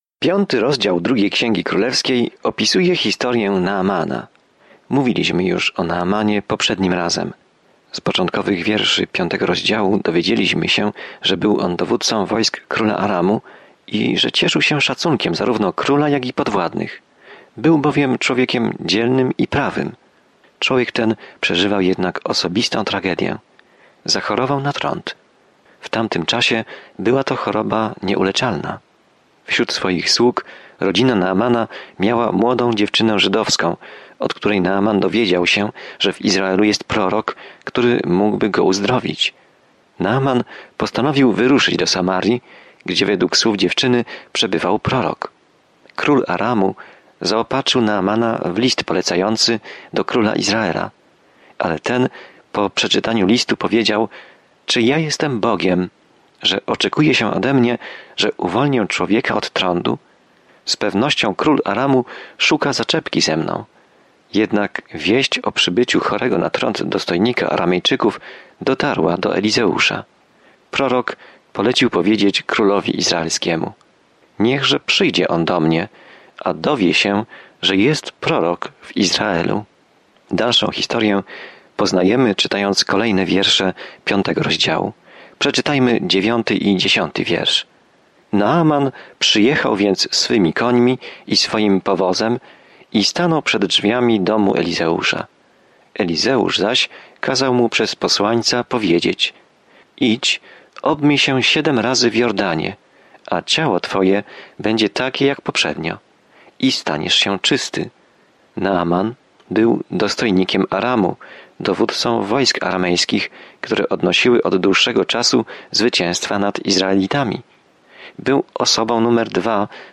Pismo Święte 2 Królewska 5:12-27 2 Królewska 6:1-7 Dzień 3 Rozpocznij ten plan Dzień 5 O tym planie Księga Drugiej Księgi Królewskiej opisuje, jak ludzie stracili z oczu Boga i jak On o nich nigdy nie zapomniał. Codziennie podróżuj przez Księgę 2 Królów, słuchając studium audio i czytając wybrane wersety ze słowa Bożego.